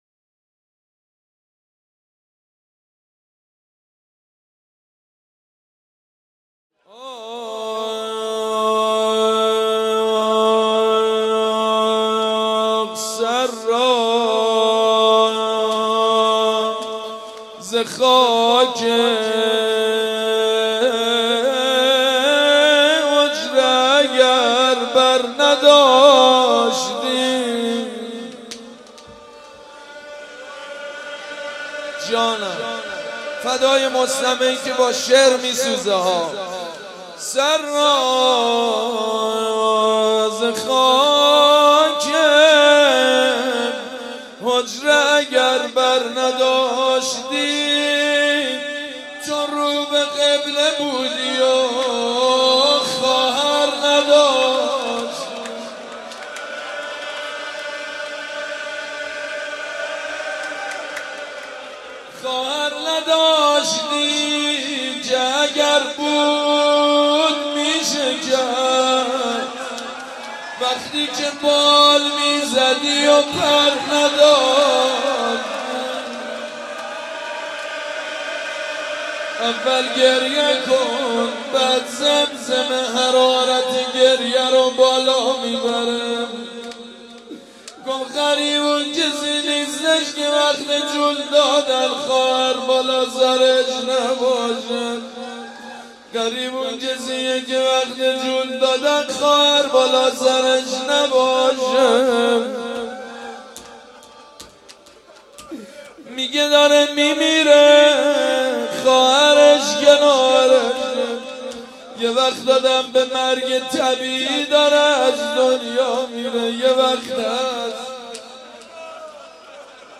«شهادت امام جواد 1393» روضه: سر را ز خاک حجره اگر بر نداشتی